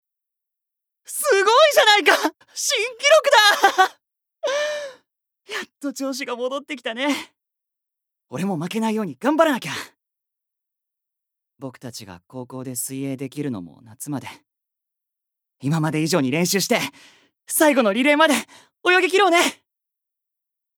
Voice Sample
セリフ２